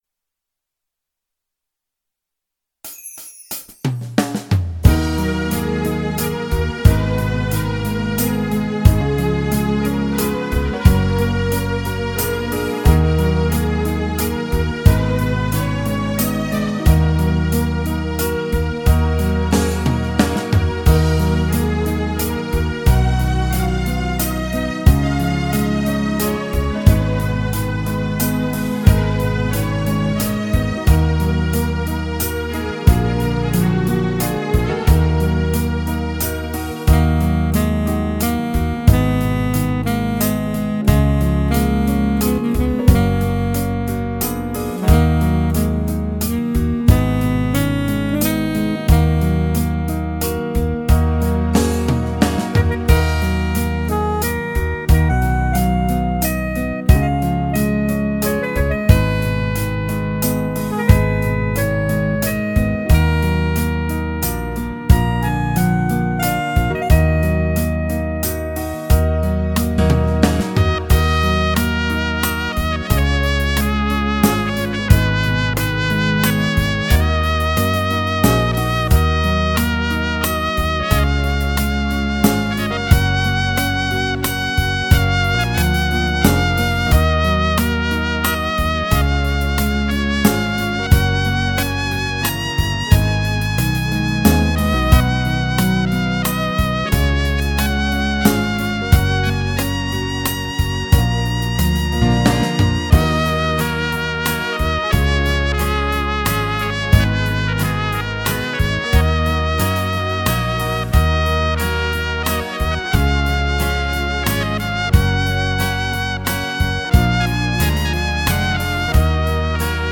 Enhance your Simcha with high quality pre-recorded music.